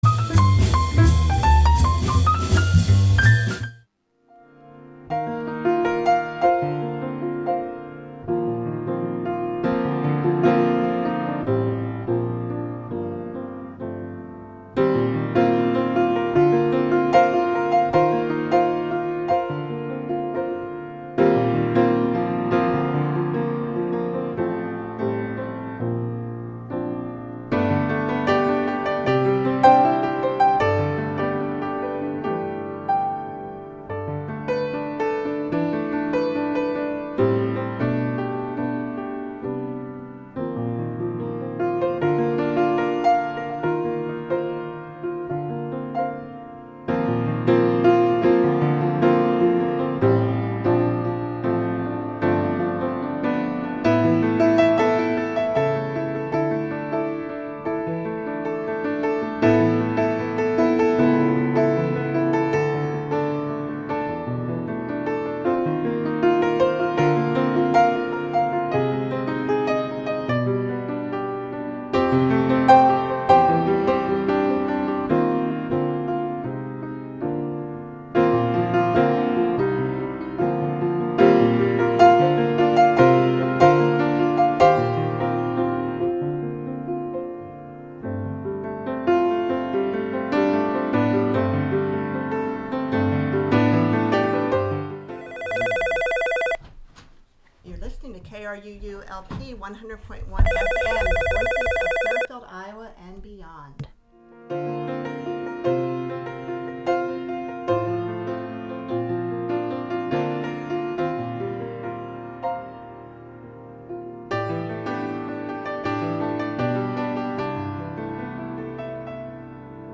It is an interview filled with readings and quotes enough to get you through your toughest writers block.